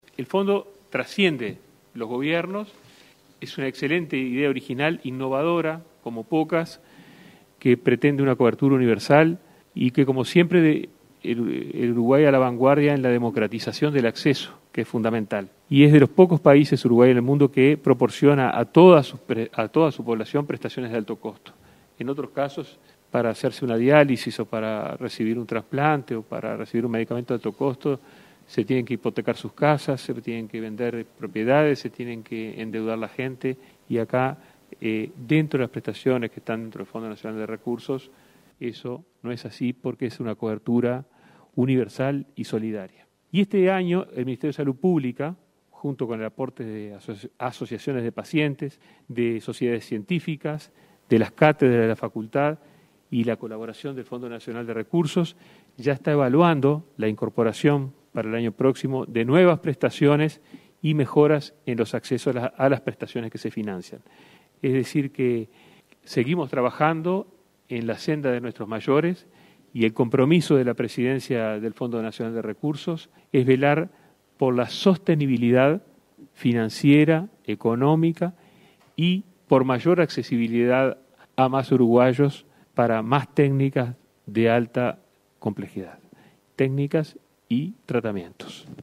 Escuche las declaraciones del ministro de Salud Pública, Daniel Salinas